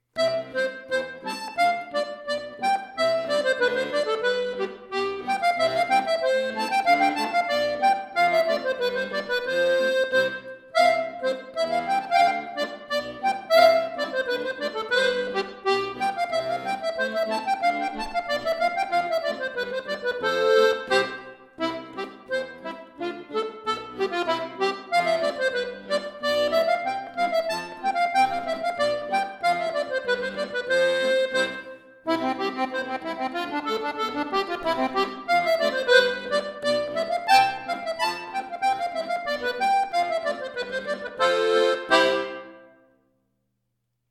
Folksong , Irish